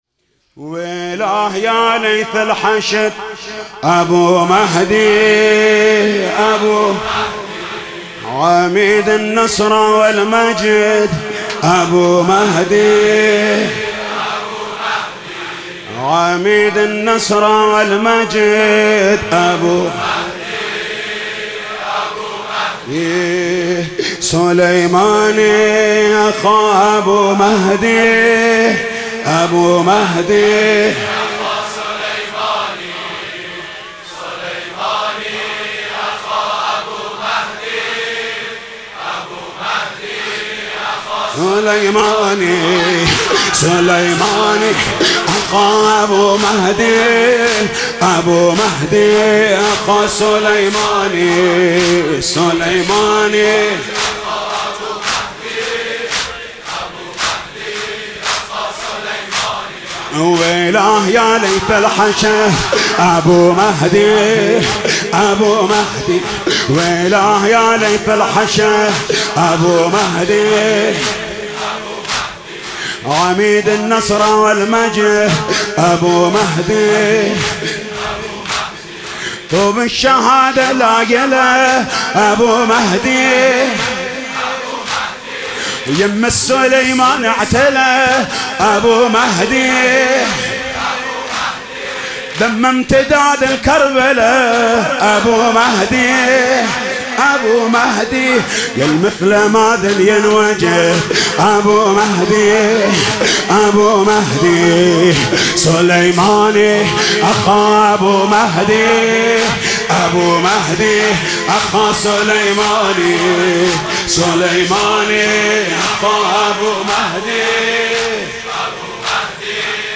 زمینه _ حاج محمود کریمی
اربعین شهید سپهبد سردار قاسم سلیمانی و ابو مهدی المهندس